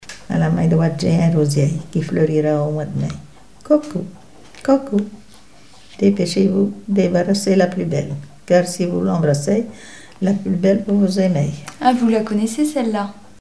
ronde à embrasser
Témoignages sur les folklore enfantin
Pièce musicale inédite